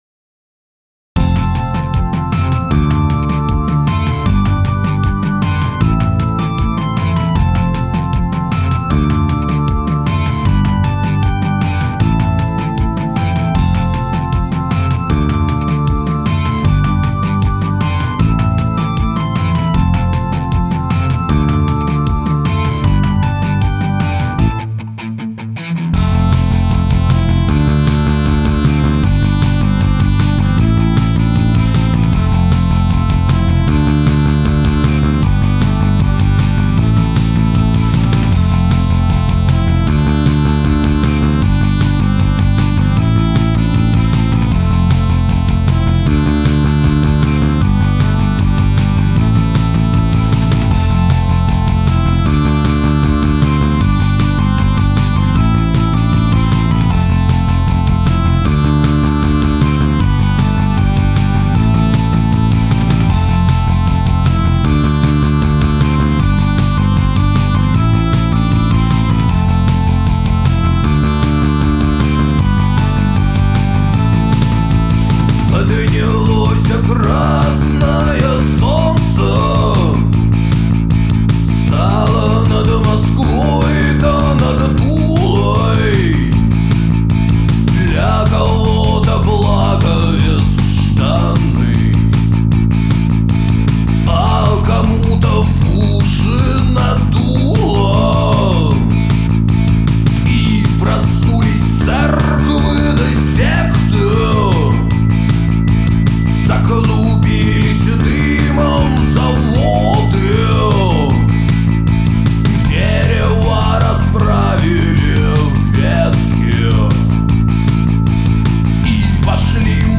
Стерео stereo